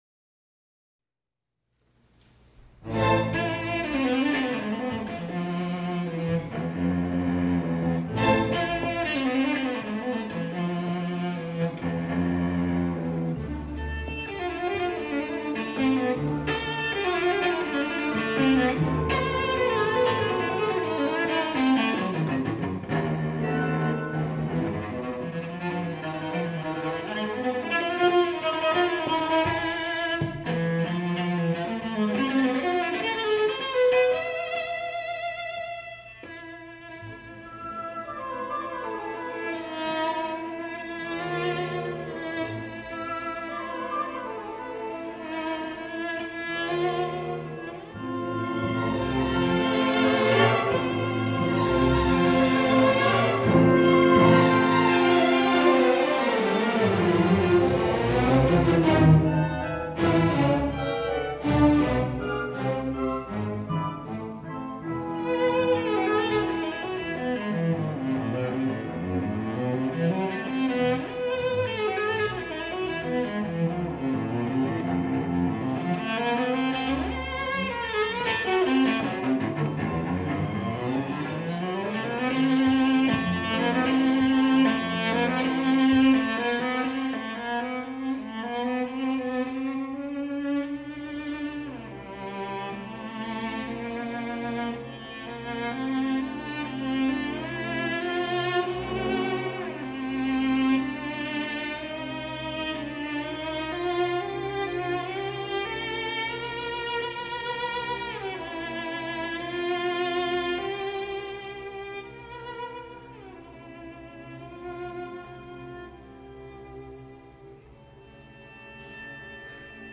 这张现场录音唱片可能考虑到乐队在奏强音时会“淹没”独奏大提琴，因此整张唱片的录音，独奏大提琴靠话筒较近，音量也较大。
现场录音能达到如此水准，制成立体声CD，已属不易，哪怕录音中不可避免地夹带了少许咳嗽声也无伤大雅。
第一乐章开头a小调主和声的处理和紧随之后的独奏大提琴的入场就是让人感到了一种难言的悲调，然而却是壮烈的。
这样的快节奏也是其他演奏中少见的。